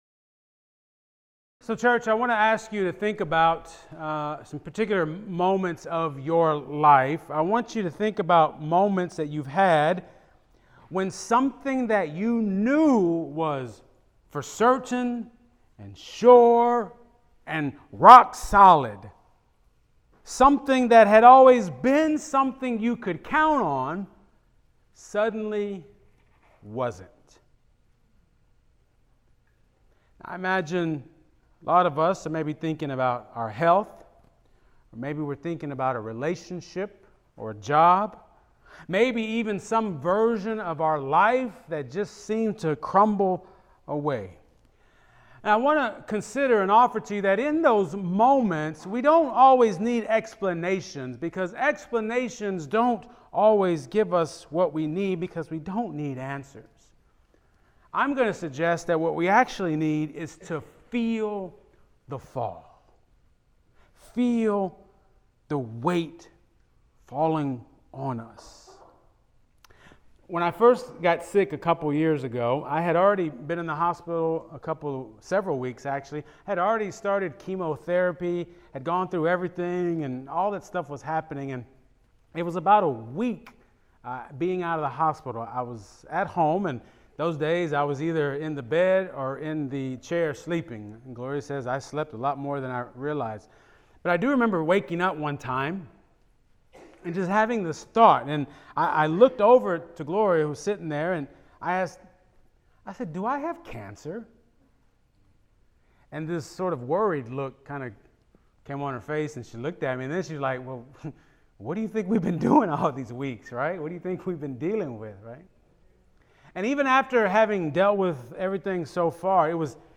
Key Takeaways from the Sermon